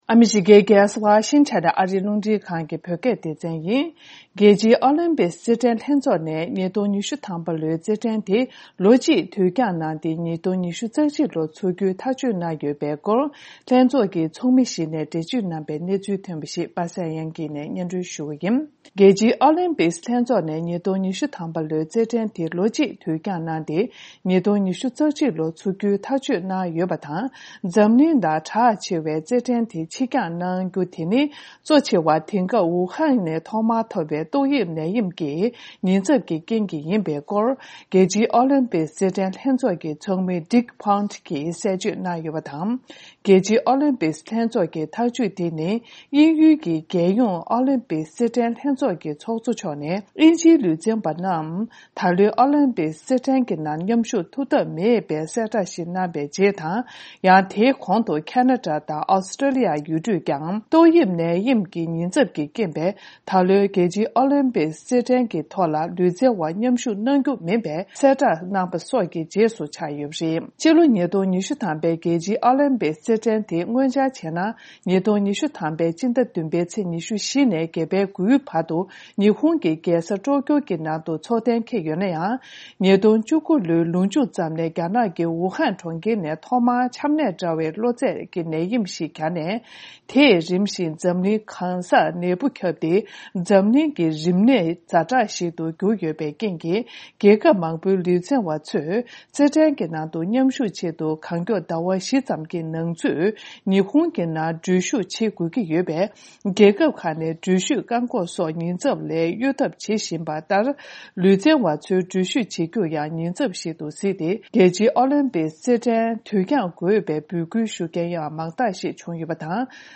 གསར་འགྱུར་ཐོན་པ་